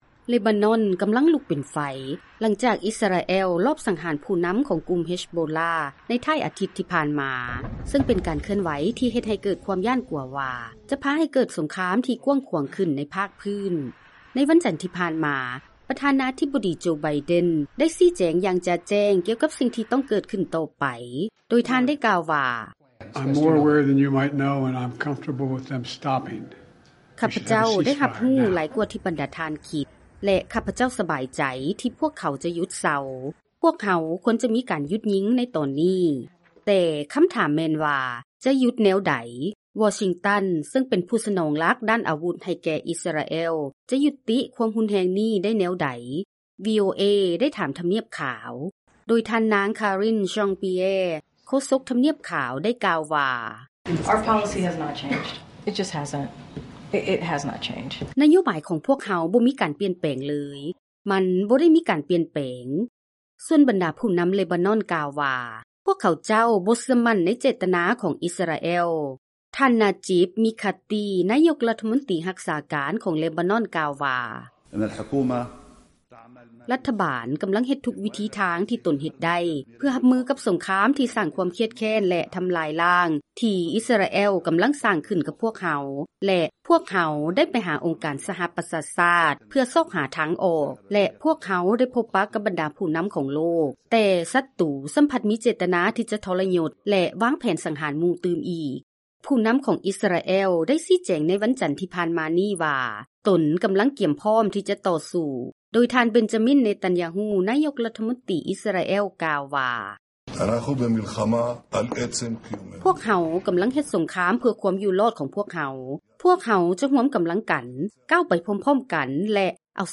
President Joe Biden:
Karine Jean-Pierre, White House Press Secretary:
Benjamin Netanyahu, Israeli Prime Minister:
Jean-Noël Barrot, French Foreign Minister: